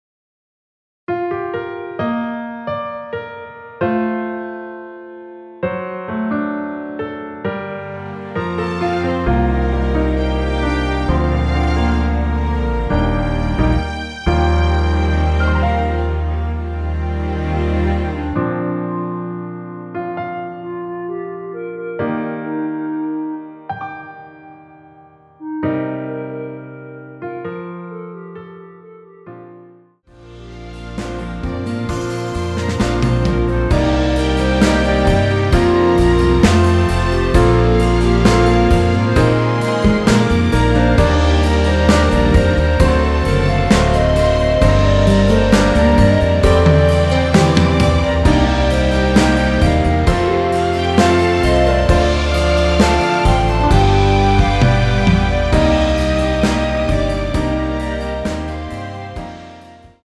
원키에서(-2)내린 멜로디 포함된 MR입니다.
Bb
앞부분30초, 뒷부분30초씩 편집해서 올려 드리고 있습니다.
중간에 음이 끈어지고 다시 나오는 이유는